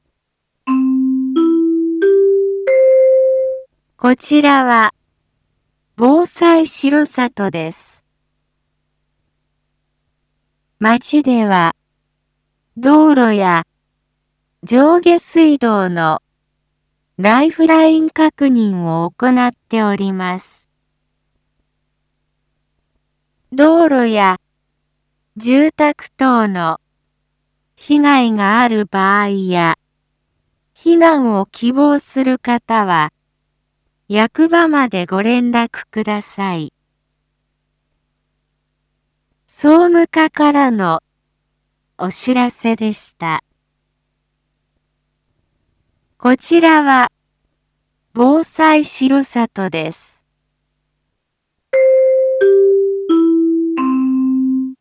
Back Home 一般放送情報 音声放送 再生 一般放送情報 登録日時：2022-03-17 00:50:55 タイトル：R4.3.17 臨時放送 地震発生確認 インフォメーション：こちらは、防災しろさとです。 町では、道路や上下水道のライフライン確認を行っております。